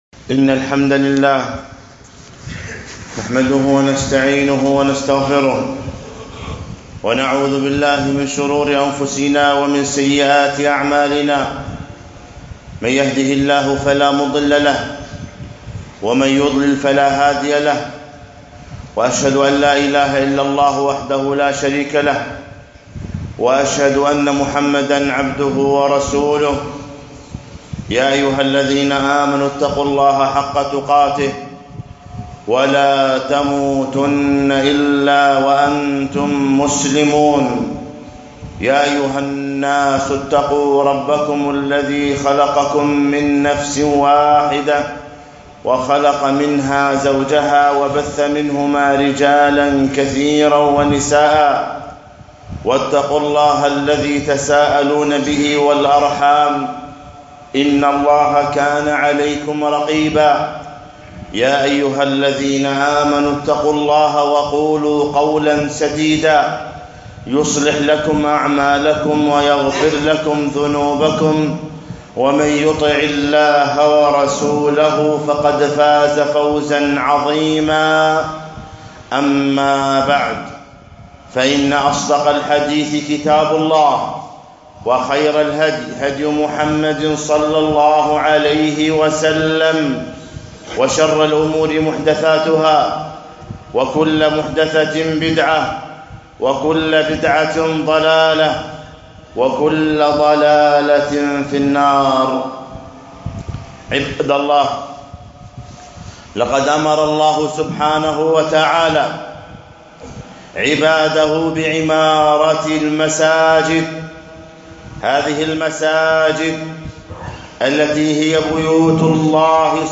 آداب المسجد الخطبة الأولى